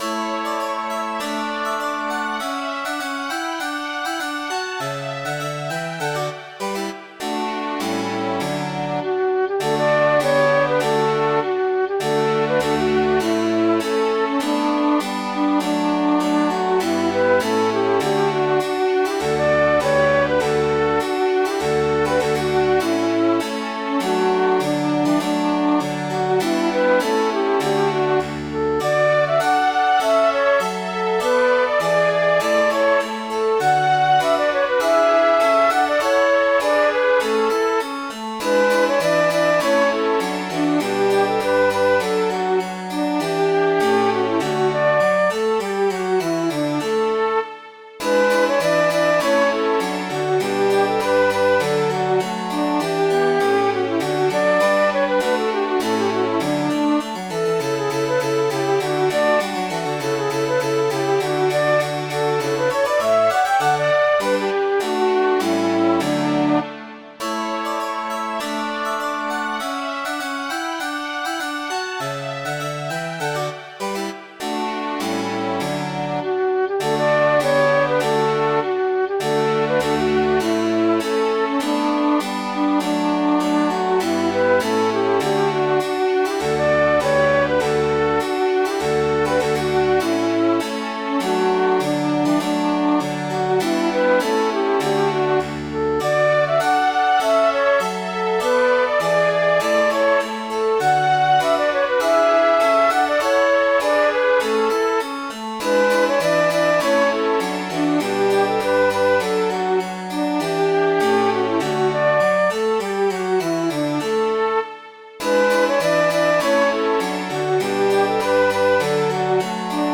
This Scottish tune was written in 1773 by Thomas Carter.
nannie.mid.ogg